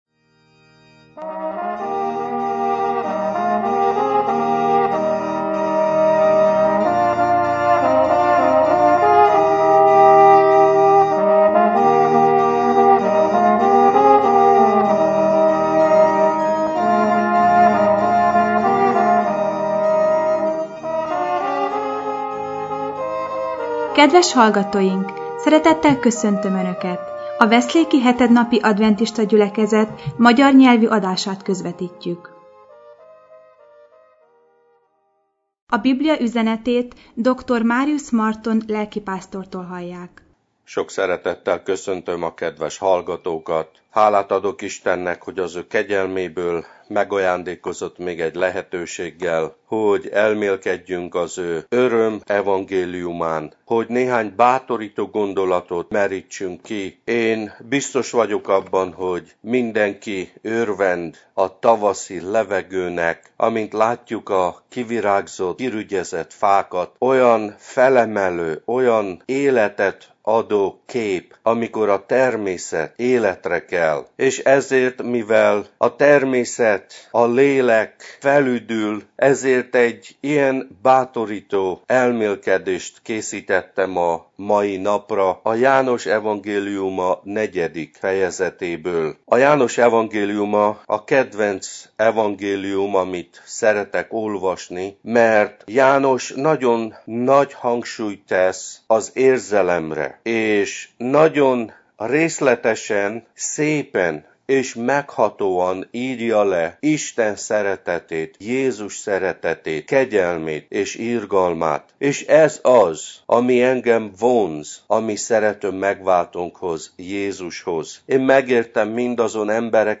Igét hirdet